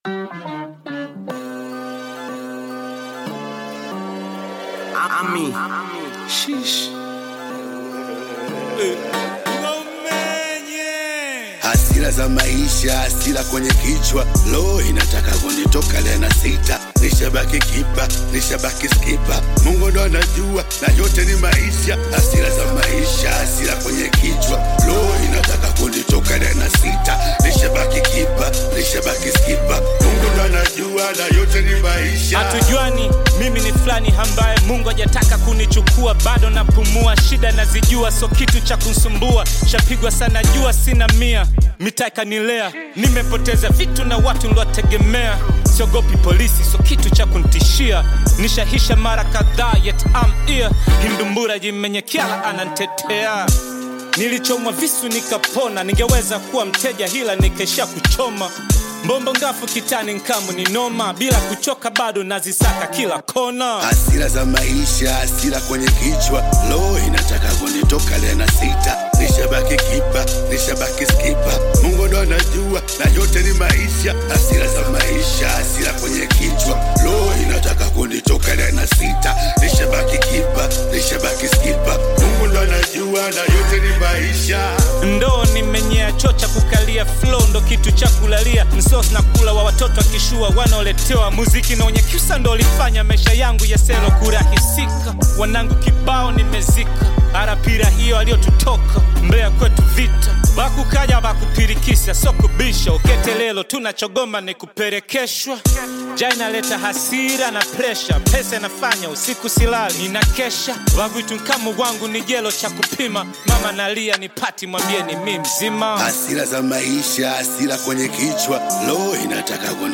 Tanzanian hip hop
is a powerful rap record
Through deep verses and emotional delivery